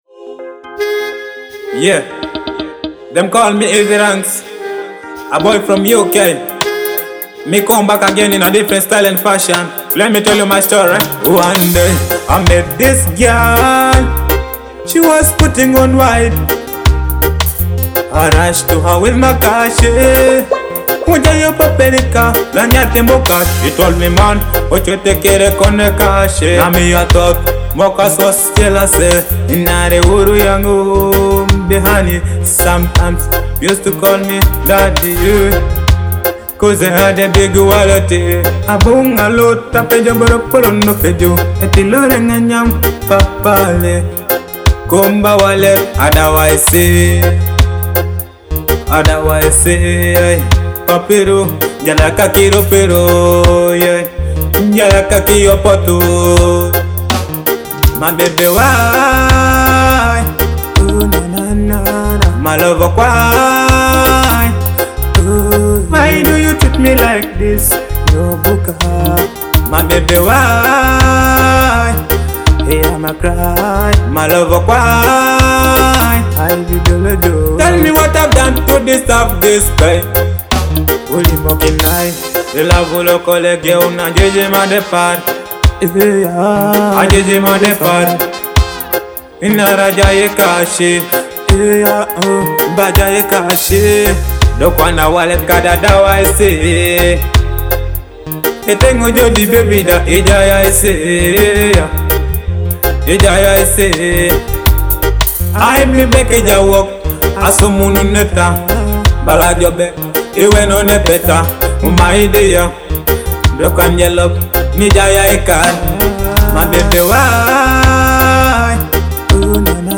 a powerful Teso reggae song from Eastern Uganda.
rich vocals and conscious style